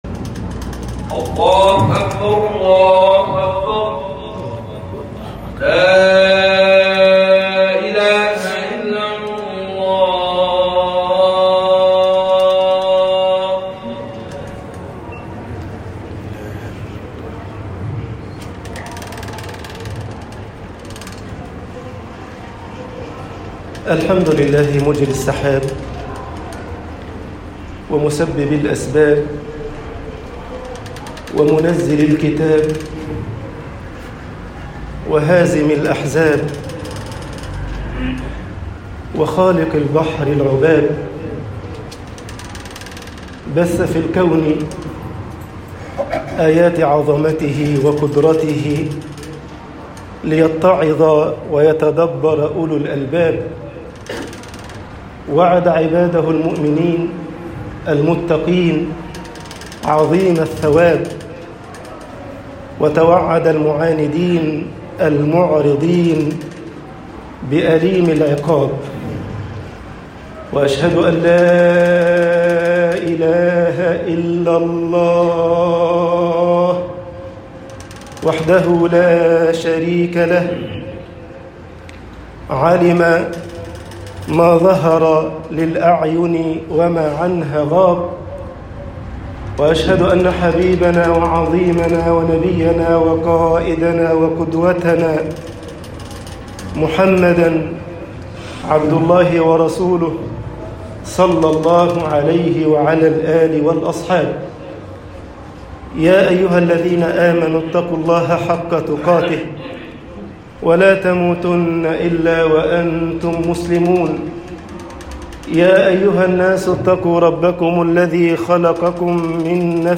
خطب الجمعة
khutbah_alinsanu baina marakataini.mp3